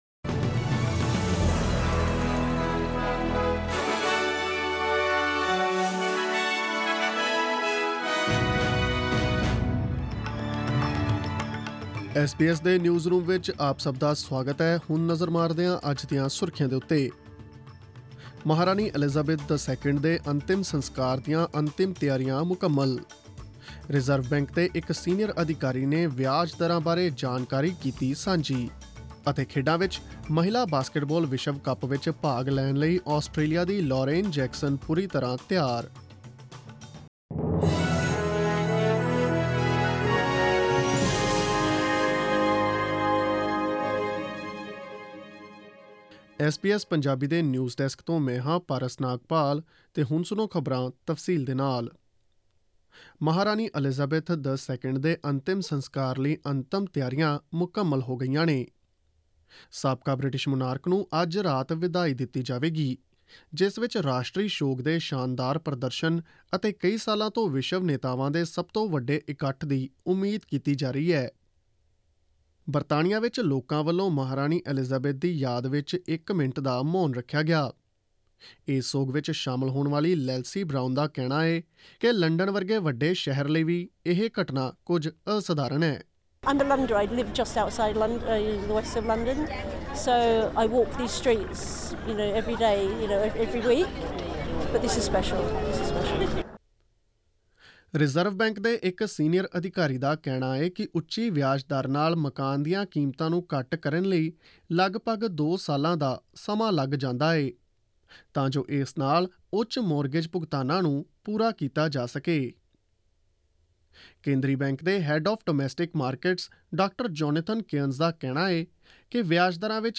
Click on the player at the top of the page to listen to this news bulletin in Punjabi.